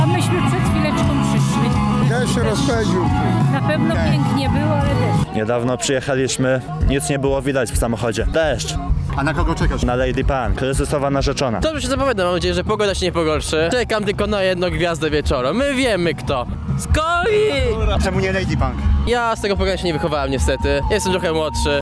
Tegoroczne Dożynki Wojewódzkie odbyły się na lotnisku trawiastym w Świdniku.
Dożynki relacja
Dozynki-relacja.mp3